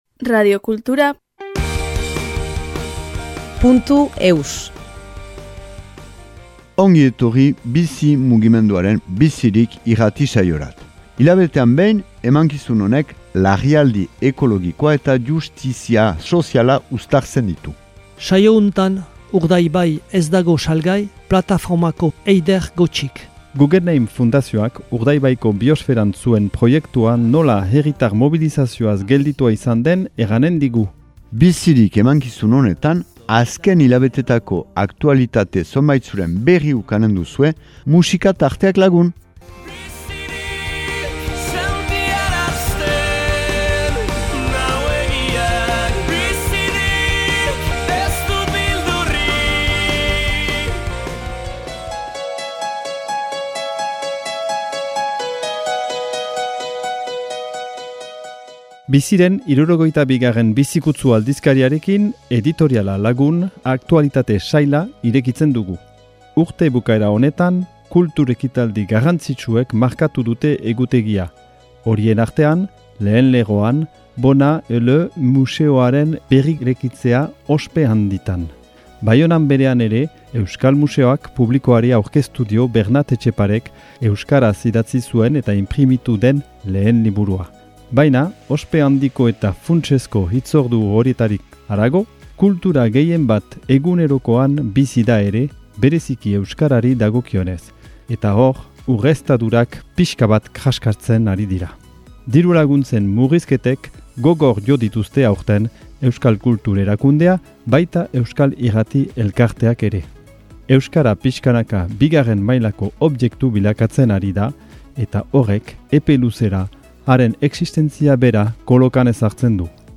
Enregistrement émission de radio en langue basque #131